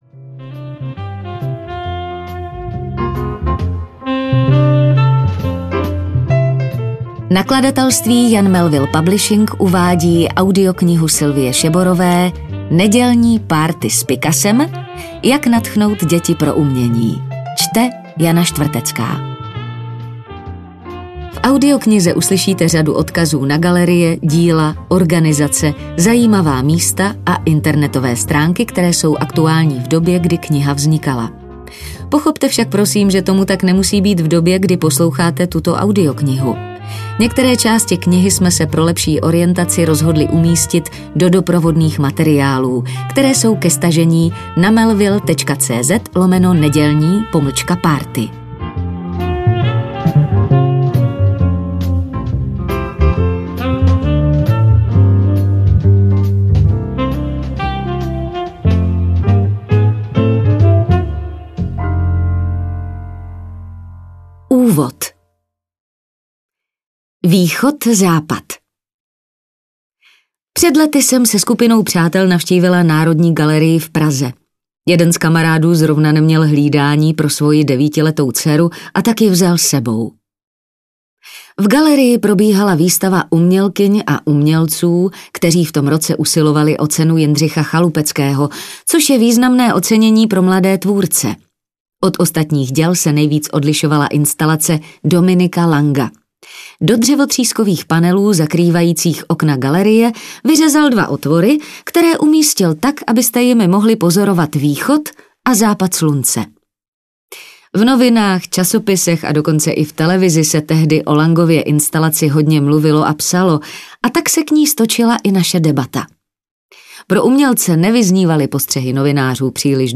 Nedělní párty s Picassem audiokniha
Ukázka z knihy